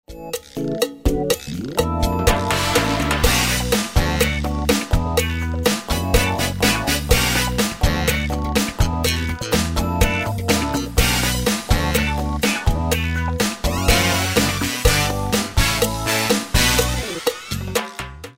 Щелчки...
Практически в любом софт синте, в некоторых пресетах, наблюдаю характерные щелчки или трескотню.
Оба записаны в СОНАРЕ 5.2, как есть (просто синты)...